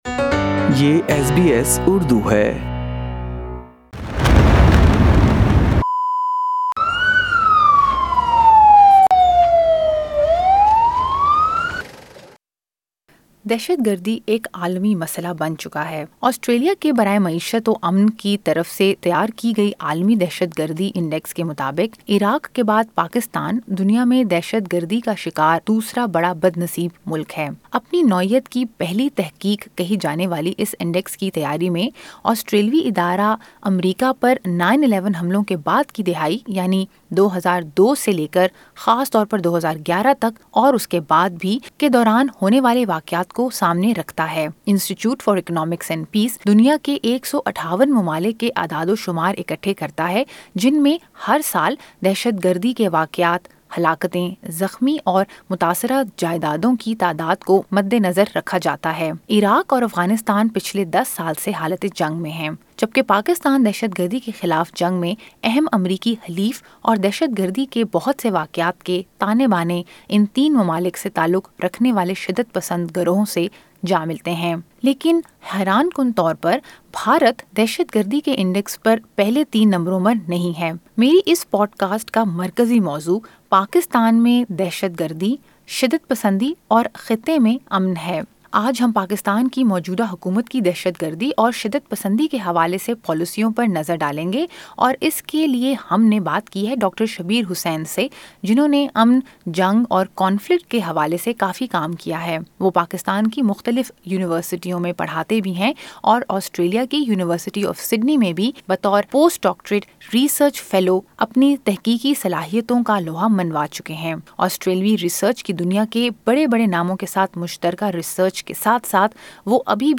They say a diaspora is the face of their home country abroad, but to understand the international image of a country vis-à-vis terrorism and extremism, it is imperative to be familiar with the government’s policies. In this podcast, SBS Urdu talks to a Pakistani academic, a member of the National Assembly visiting Australia and some Pakistani-Australians to find out how effective these policies are?